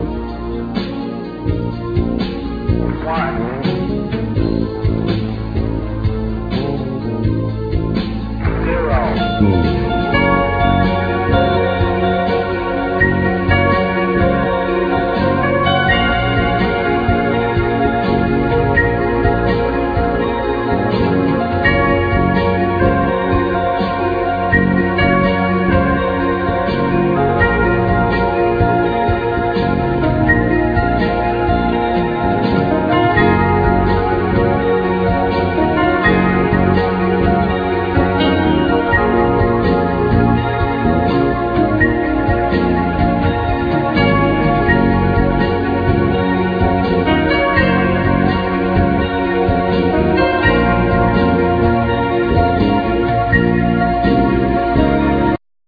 Piano,Keyboards,Programming
Guitar
Bass
Vocals